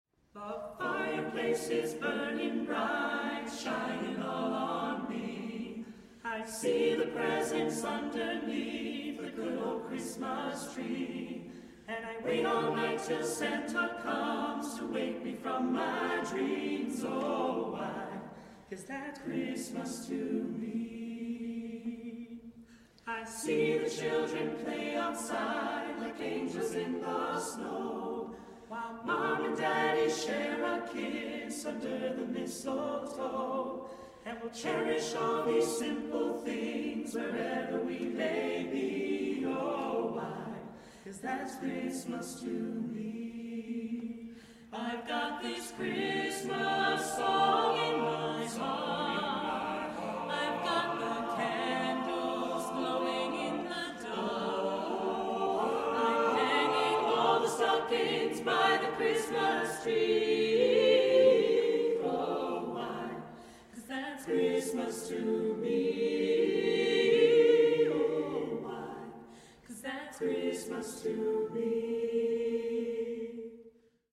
Soprano
Bass
Mezzo-soprano
Tenor